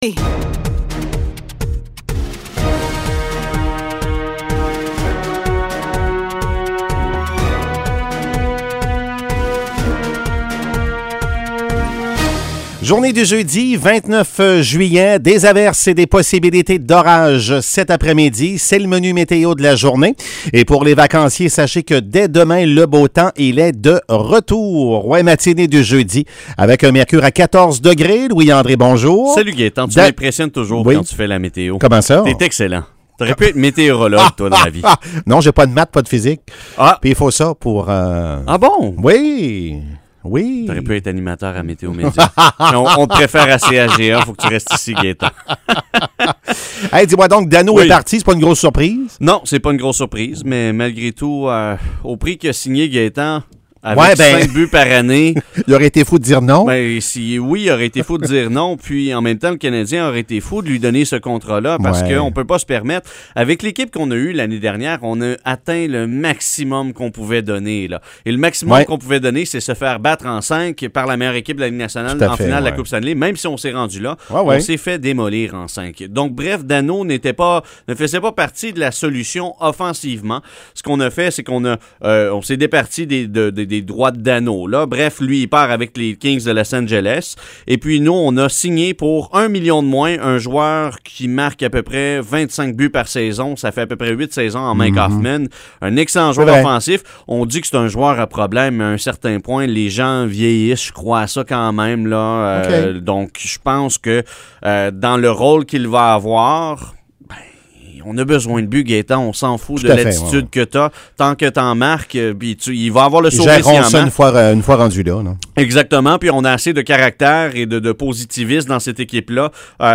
Nouvelles locales - 29 juillet 2021 - 7 h